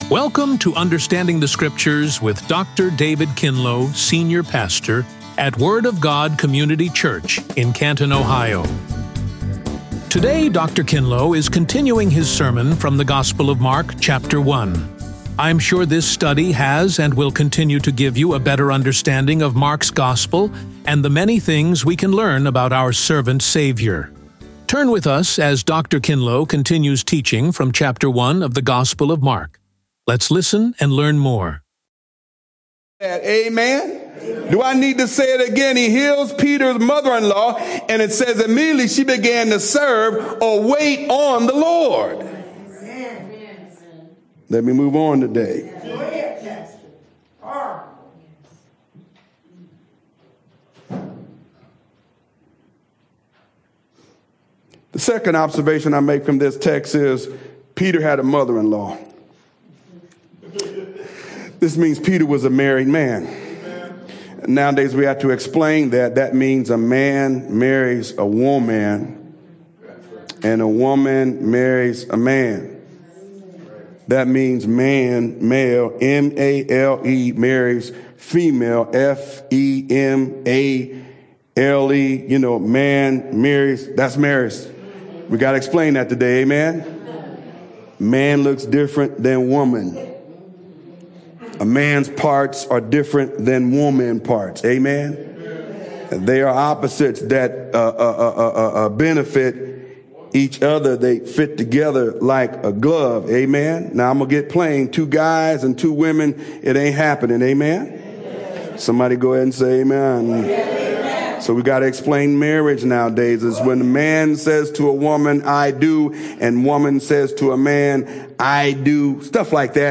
Radio Sermons | The Word of God Community Church